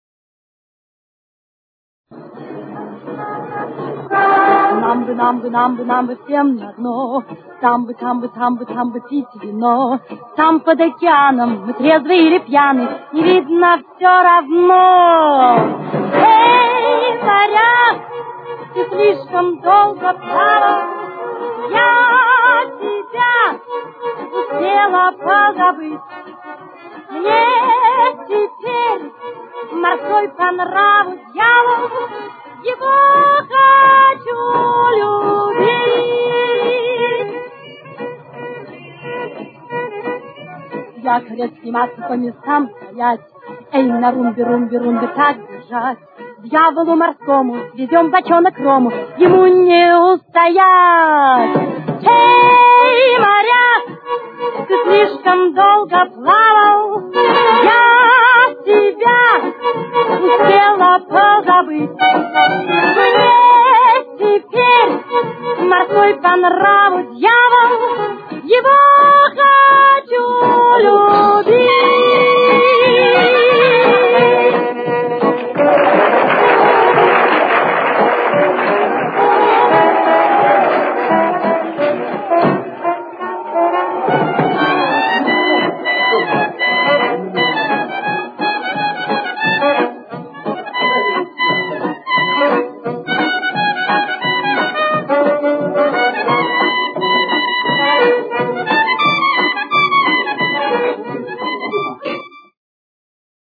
с очень низким качеством (16 – 32 кБит/с)
Си-бемоль минор. Темп: 174.